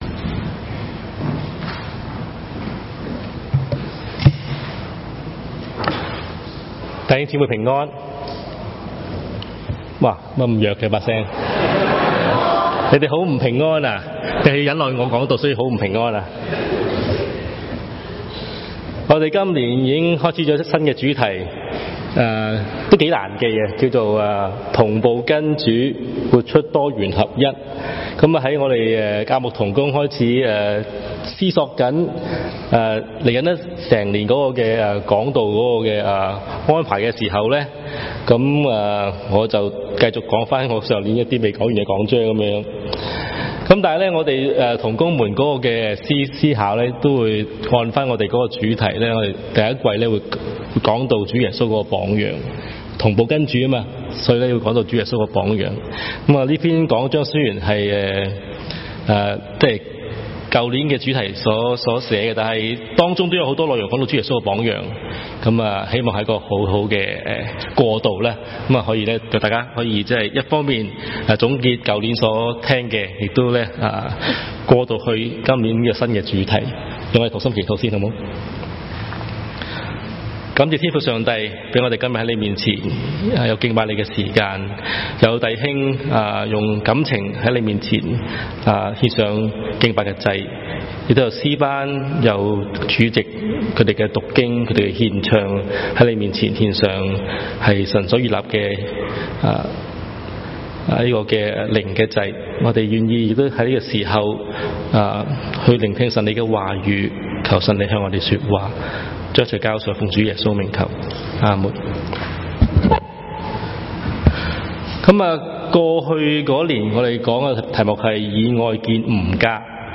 牛頓粵語崇拜 , 講道